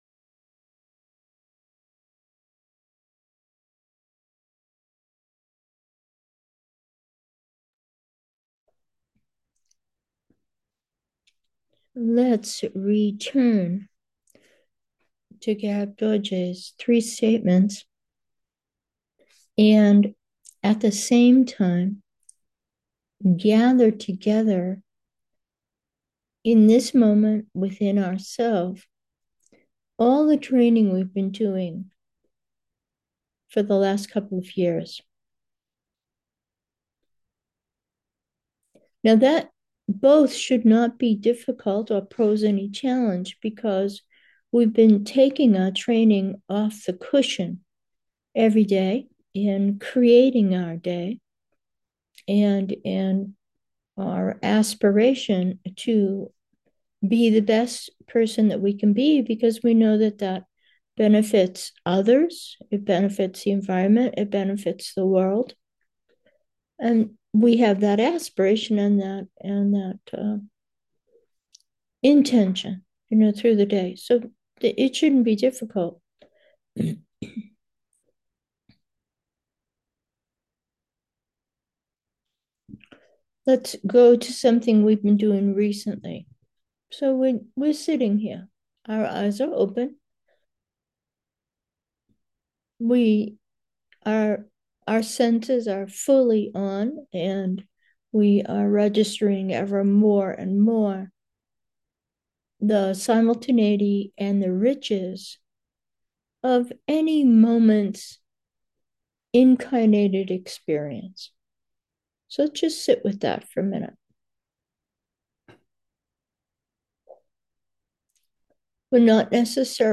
Meditation: being present 1